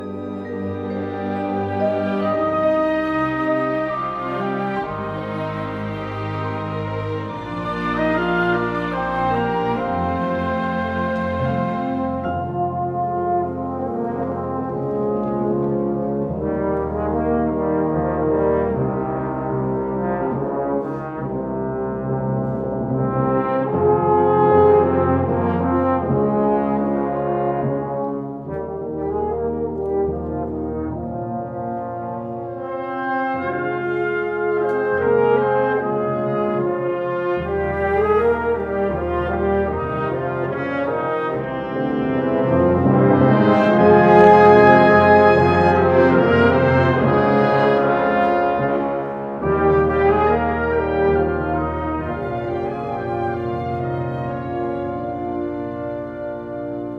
for symphony orchestra